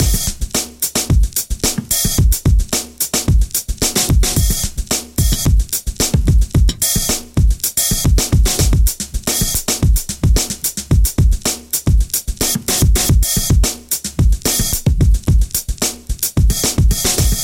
硬808陷阱鼓
Tag: 80 bpm Trap Loops Drum Loops 2.02 MB wav Key : Unknown